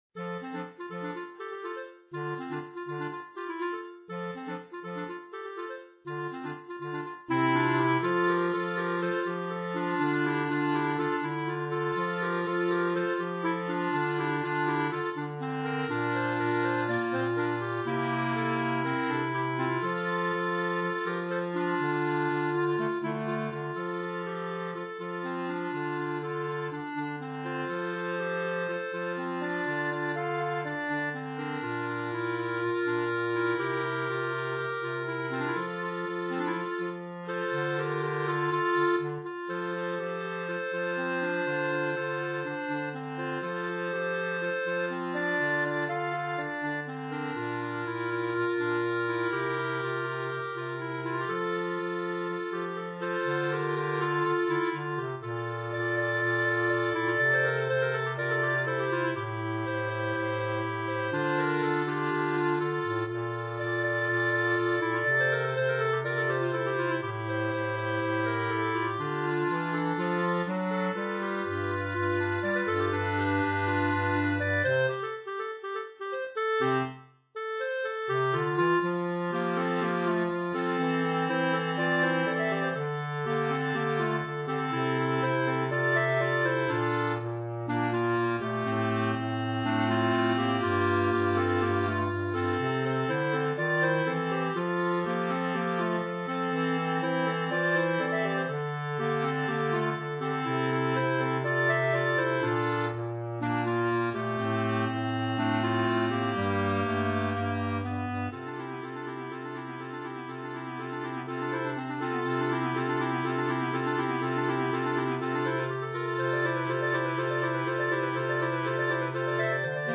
B♭ Clarinet 1 B♭ Clarinet 2 B♭ Clarinet 3 Bass Clarinet
单簧管四重奏
这首略带怀旧感的旋律，不仅深受年轻一代的喜爱，也广受各个年龄层听众的欢迎。